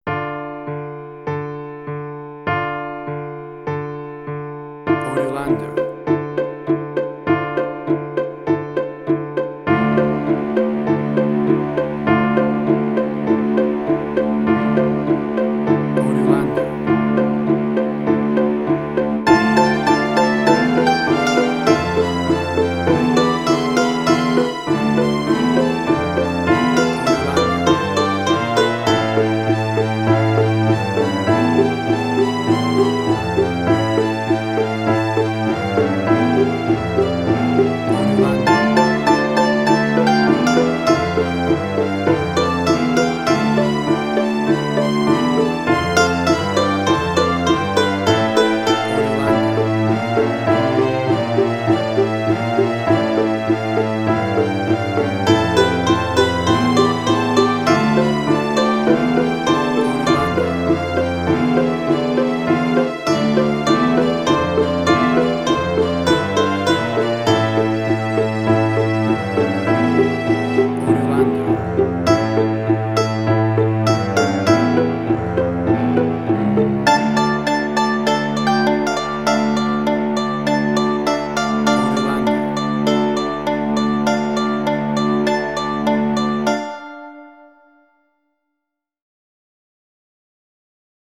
emotional music
Tempo (BPM): 100